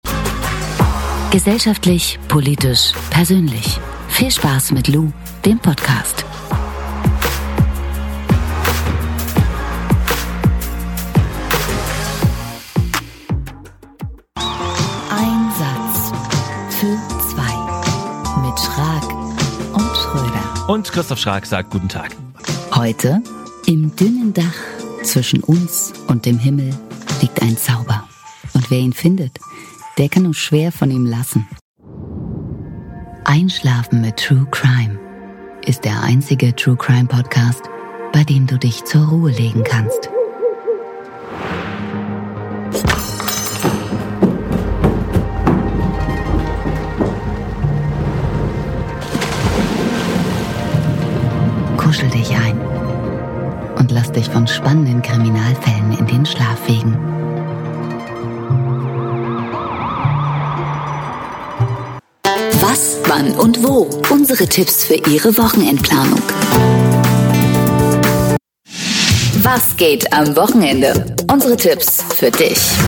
Locuteur natif